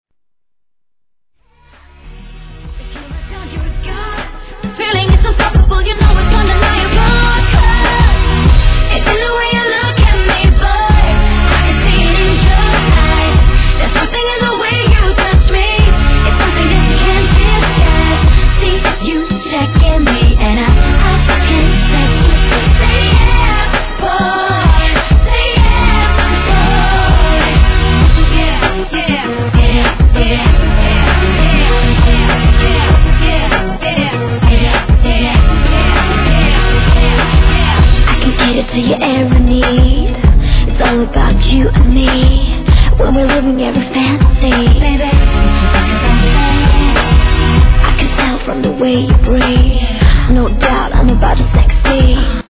HIP HOP/R&B
BPM98